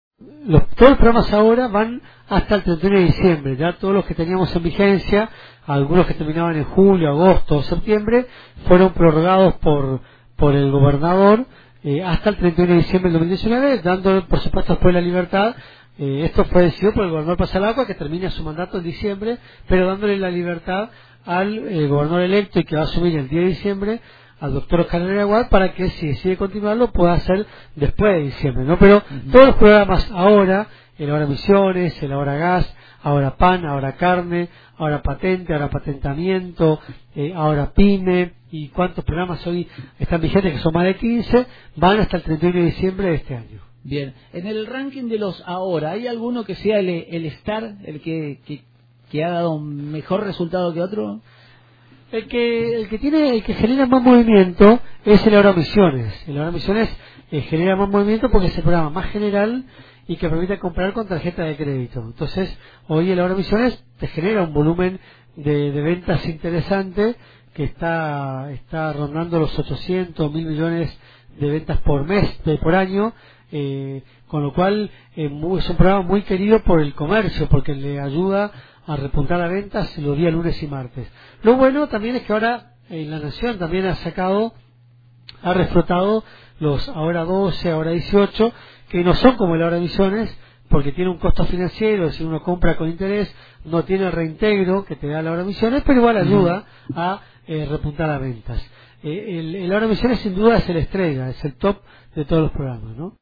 El ministro de Hacienda provincial Adolfo Safrán habló de la vigencia de los programas “Ahora”, contó cual de todos los vigentes es el más destacado y pronosticó sobre la economía del país con la llegada del presidente electo Alberto Fernández.